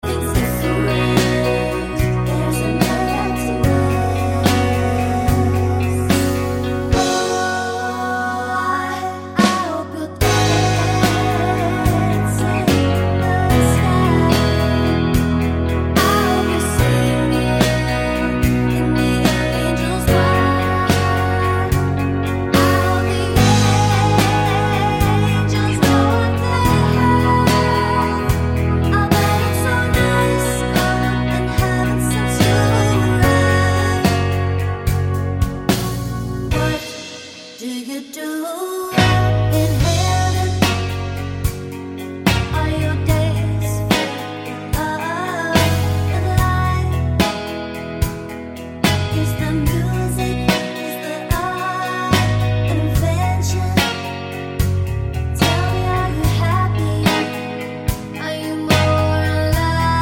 for duet Pop (2010s) 3:54 Buy £1.50